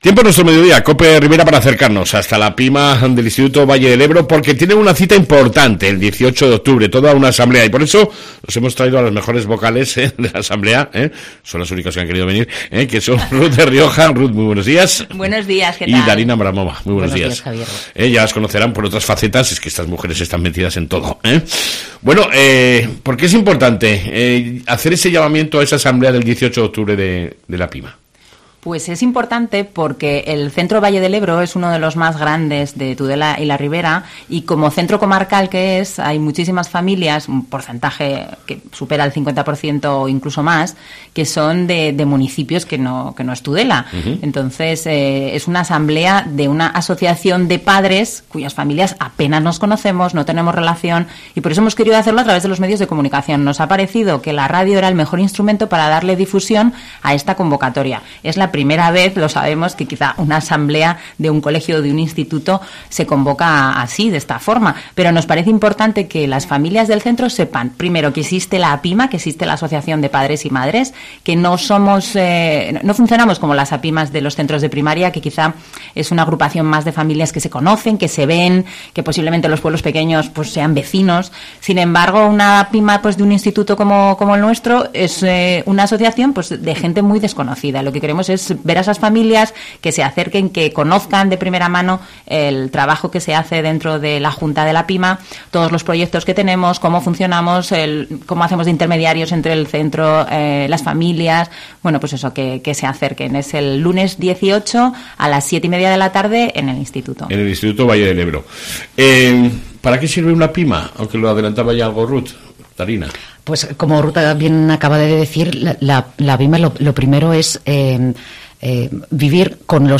AUDIO: ENTREVISTA CON LA APYMA VALLE DEL EBRO